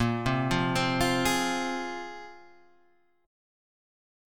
A#7sus2 Chord